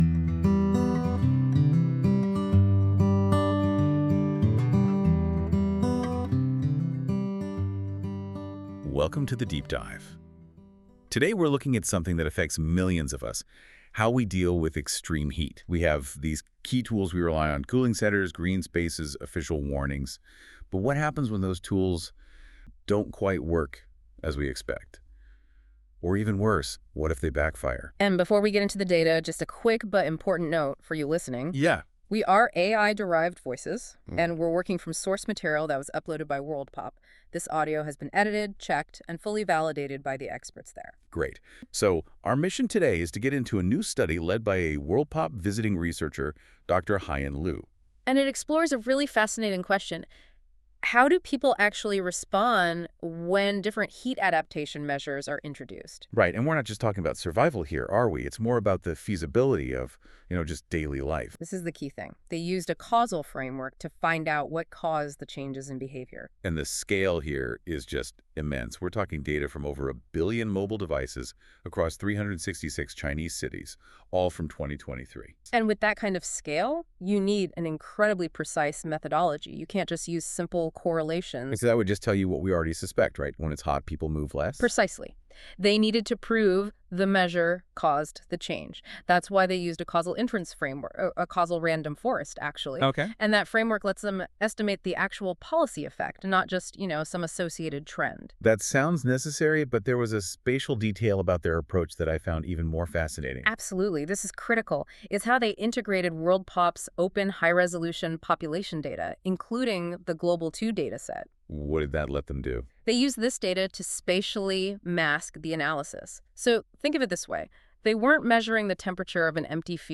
This feature uses AI to create a podcast-like audio conversation between two AI-derived hosts that summarise key points of documents - in this case the “Assessing context-dependent effectiveness of heat adaptation through human mobility under different heatwave regimes” paper linked below.
Music: My Guitar, Lowtone Music, Free Music Archive (CC BY-NC-ND)